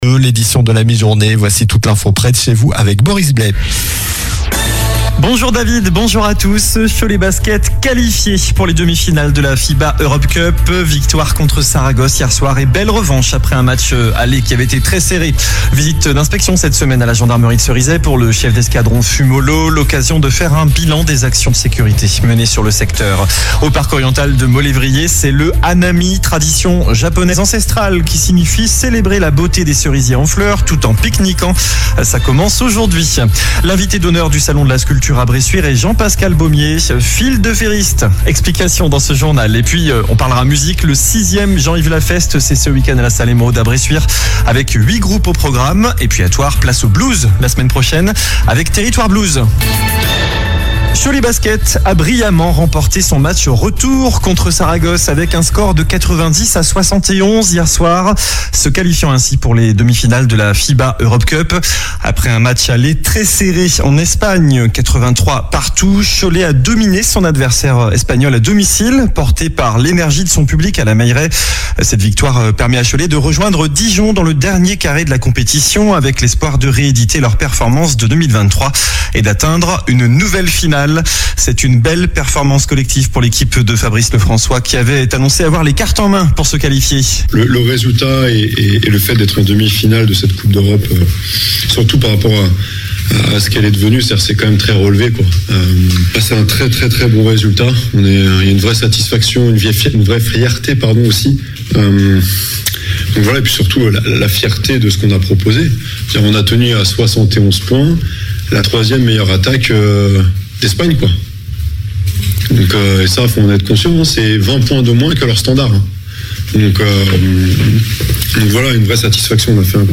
Journal jeudi 13 mars (midi)